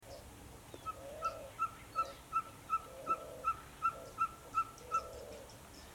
Ferruginous Pygmy Owl (Glaucidium brasilianum)
Life Stage: Adult
Location or protected area: Reserva Provincial Parque Luro
Condition: Wild
Certainty: Photographed, Recorded vocal
Cabure-chico.mp3